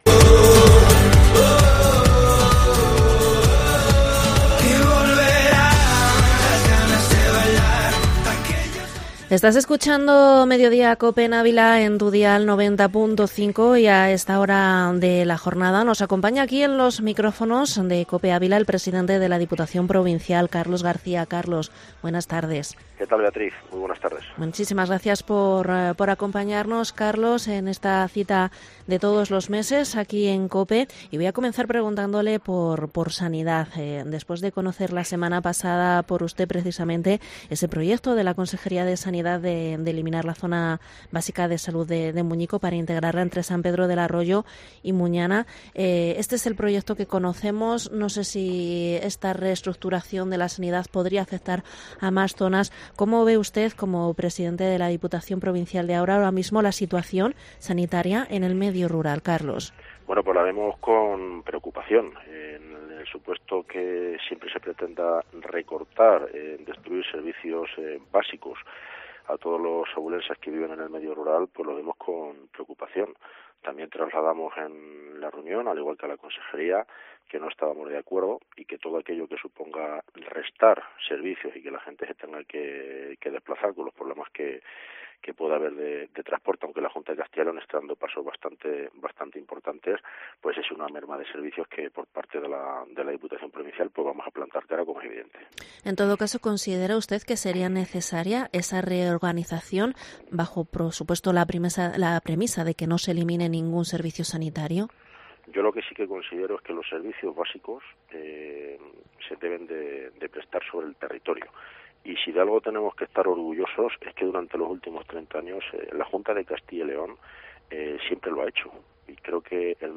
Entrevista al presidente de Diputación, Carlos García, en Mediodía Cope, 25 octubre 2022